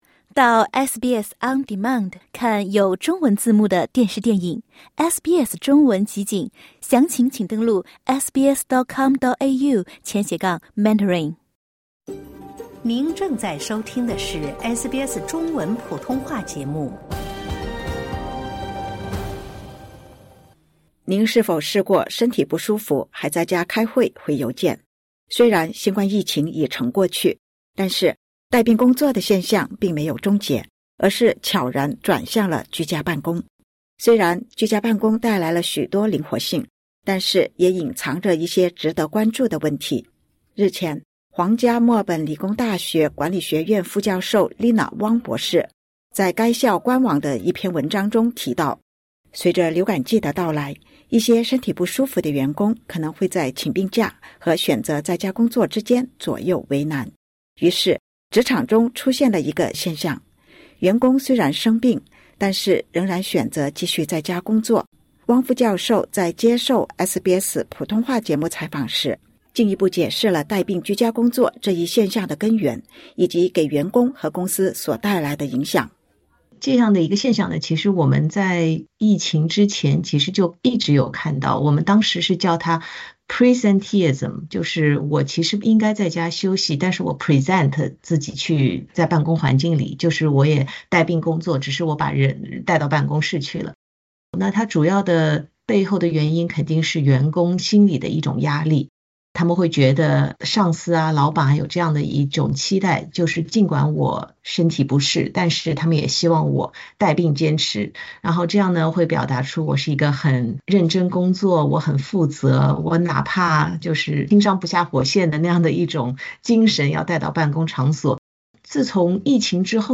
在接受SBS普通话节目采访时